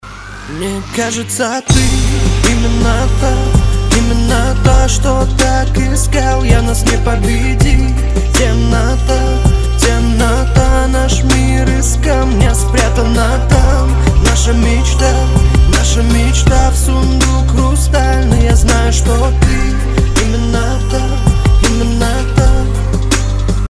• Качество: 320, Stereo
чувственные
романтичные
нежные